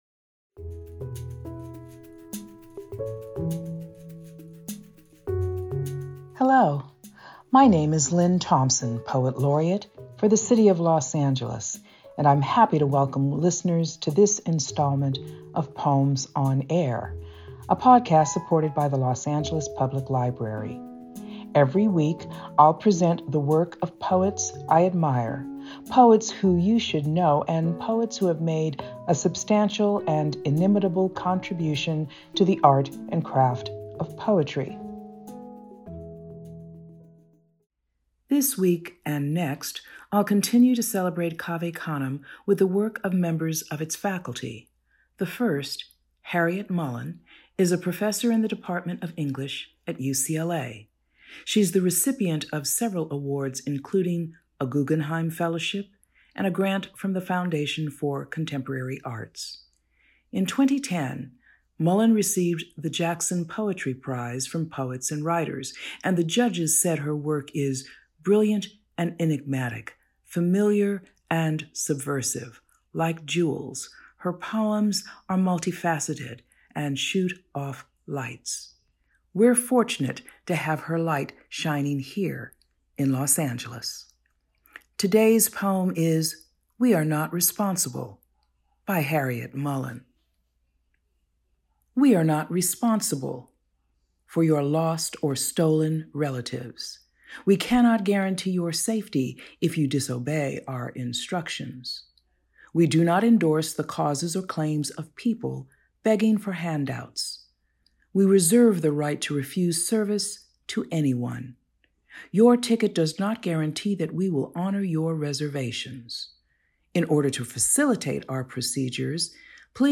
Los Angeles Poet Laureate Lynne Thompson reads Harryette Mullen’s "We Are Not Responsible".